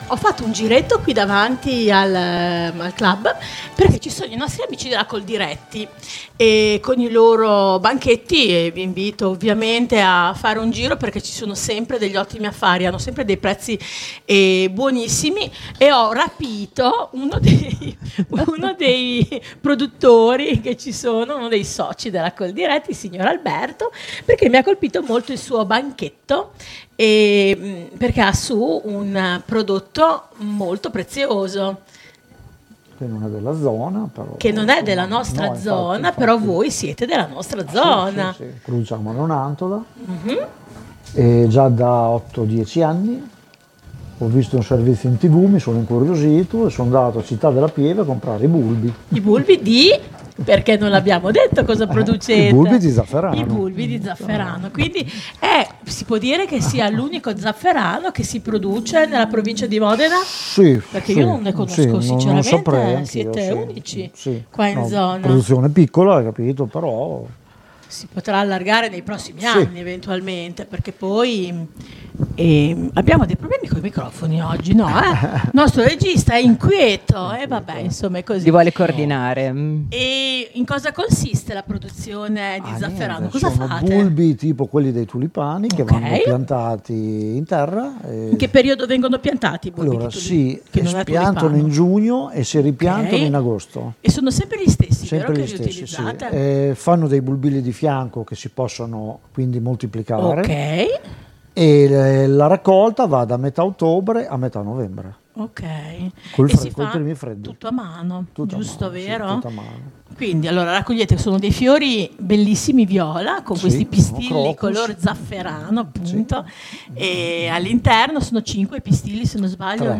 La diretta pomeridiana di Linea Radio al Clhub di viale XX Settembre a Sassuolo